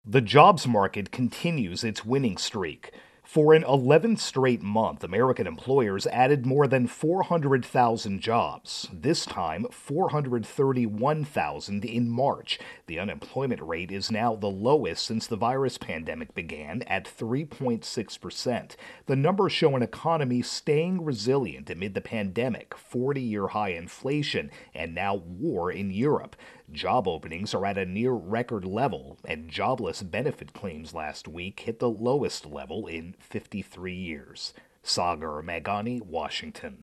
Economy-Jobs Report intro and voicer.